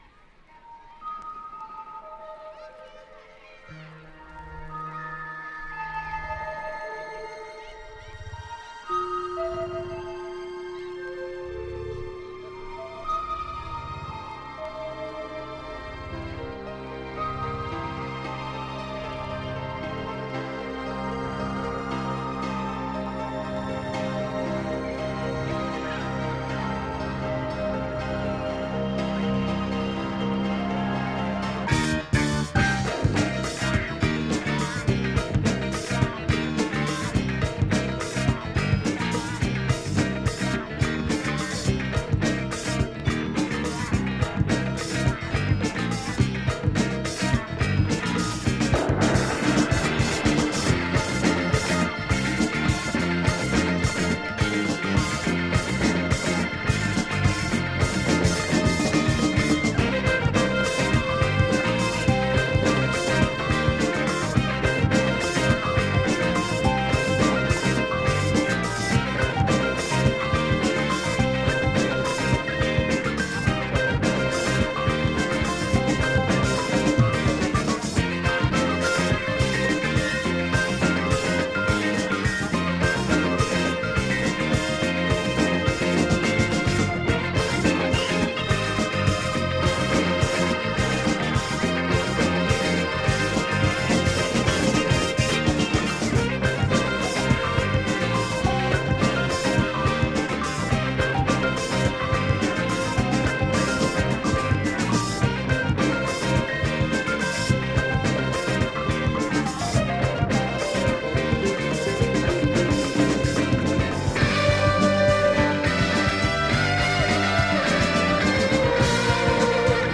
Psychedelic Jazz Rock
ニューヨーク録音によるサイケデリック・ロックプロジェクト。
【JAZZ ROCK】【BLUES ROCK】